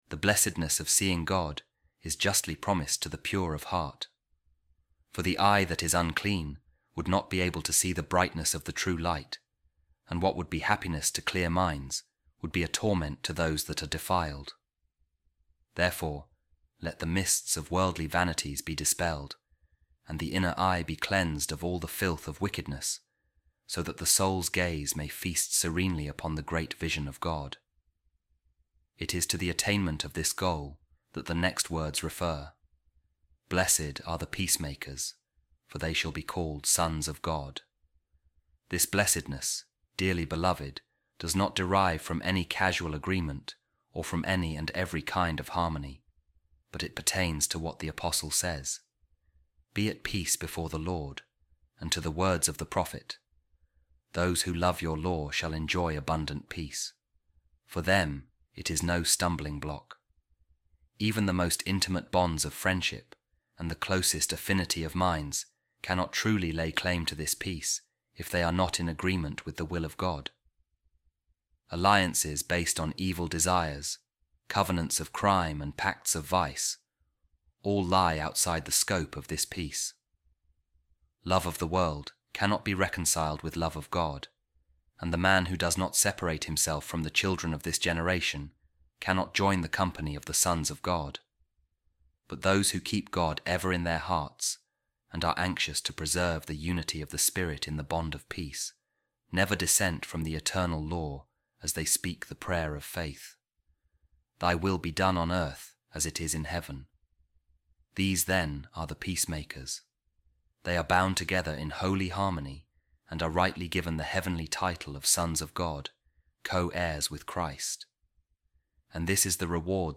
A Reading From The Sermon Of Pope Saint Leo The Great On The Beatitudes | Great Peace Have Those Who Love Your Law